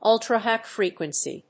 アクセント・音節últra・hìgh fréquency
音節ùl･tra･hìgh fréquency発音記号・読み方ʌ̀ltrəhàɪ-